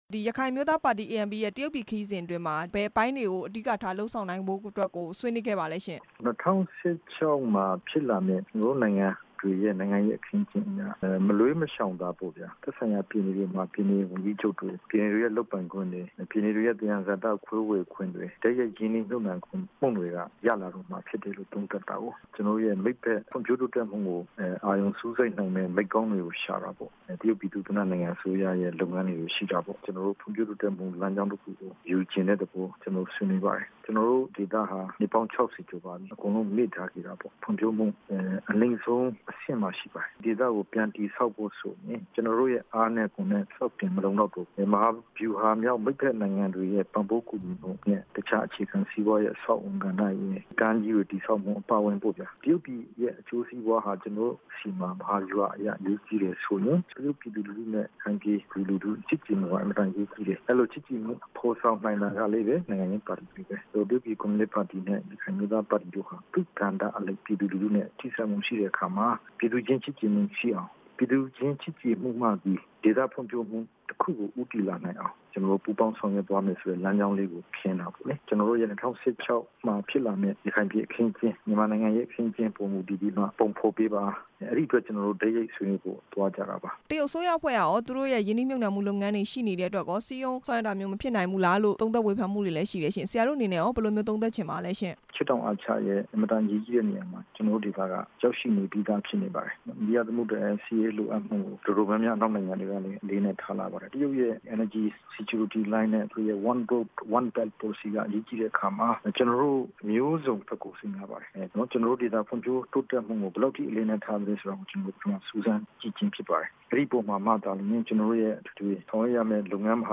ရခိုင်ပြည်နယ် ဖွံ့ဖြိုးတိုးတက်ရေးကိစ္စ ဒေါက်တာအေးမောင်နဲ့ မေးမြန်းချက်